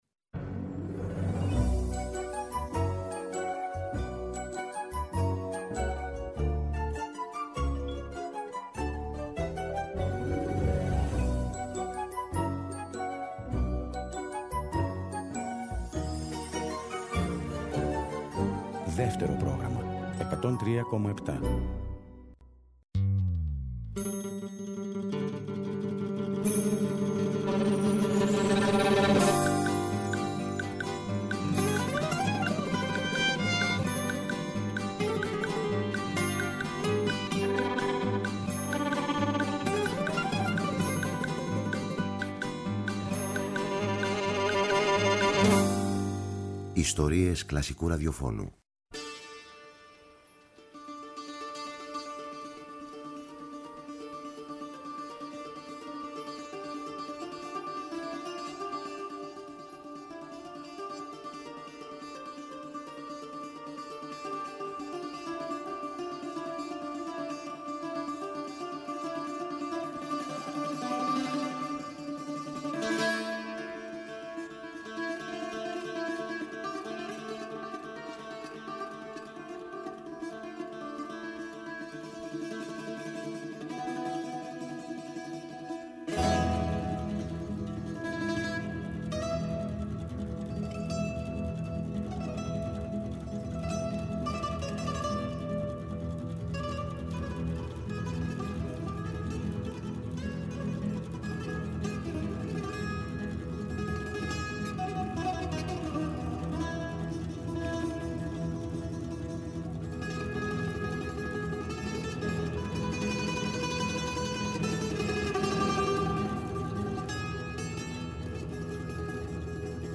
Στις 17 Νοεμβρίου του 1967 στην Κοπεγχάγη, σε μια συναυλία γεμάτη αντιστασιακό παλμό, παίζονται για πρώτη φορά αυτά τα τραγούδια. Απόσπασμα ντοκουμέντο από την σχεδόν τρίωρης διάρκειας συναυλία, θα ακούσουμε στην αποψινή εκπομπή, αφού οι περιπέτειες των Ελλήνων μουσικών “έξω από τα σύνορα” συνεχίζονται.